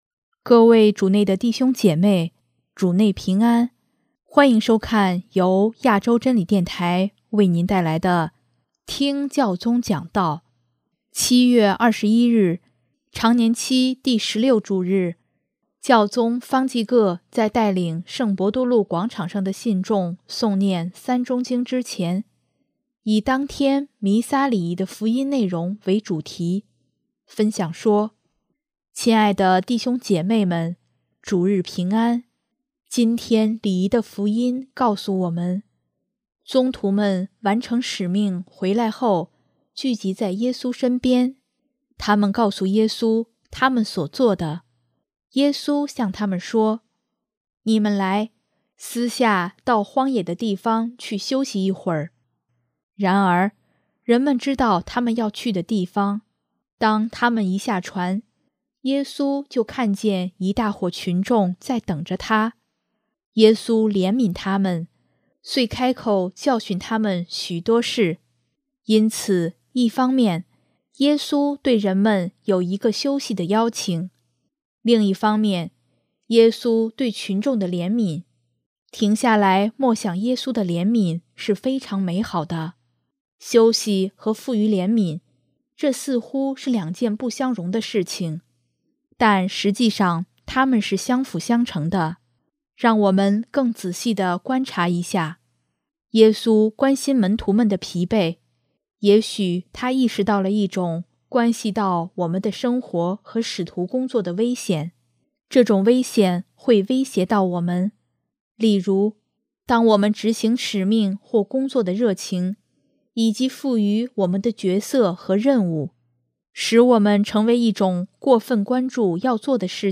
7月21日，常年期第十六主日，教宗方济各在带领圣伯多禄广场上的信众诵念《三钟经》之前，以当天弥撒礼仪的福音内容为主题，分享说：